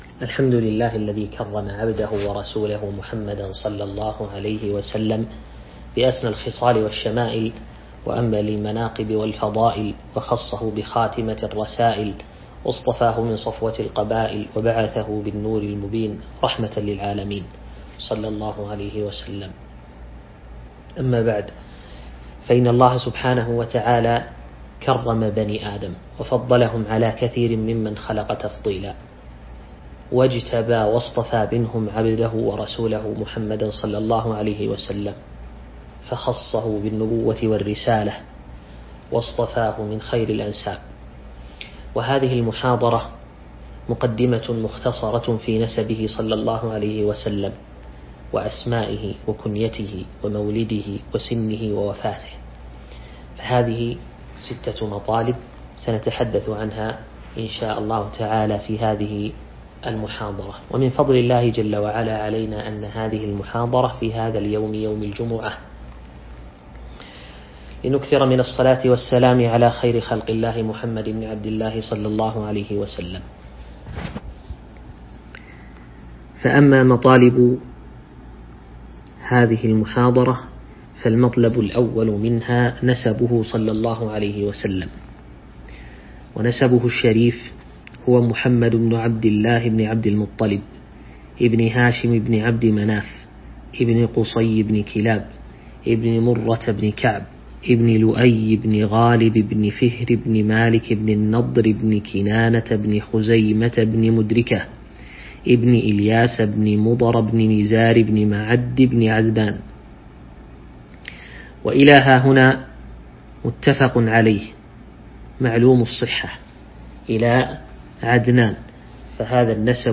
تاريخ النشر ٢٨ رجب ١٤٤٢ هـ المكان: المسجد النبوي الشيخ